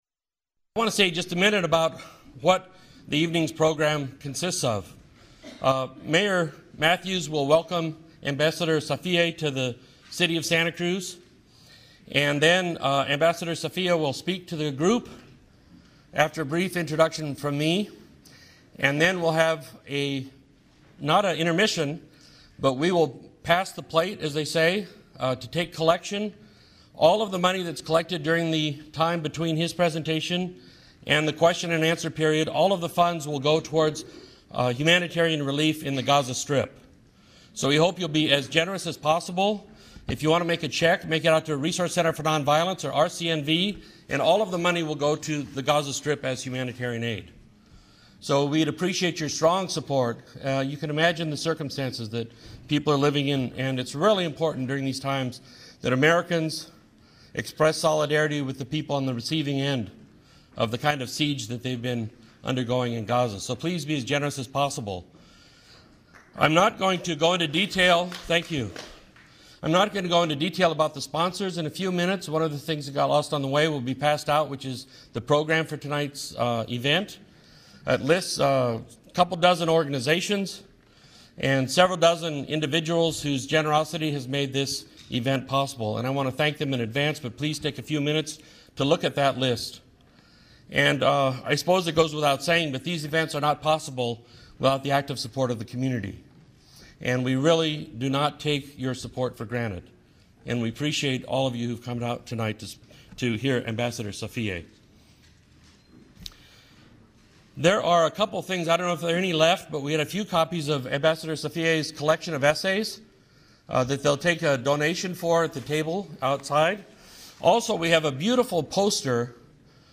He spoke on July 31st at the Veterans Memorial Hall in Santa Cruz, CA to about 300 people and took questions from the crowd after his presentation.
Then Ambassador Safieh speaks for about half an hour.
ambassador_afif_safieh_intro_and_speech.mp3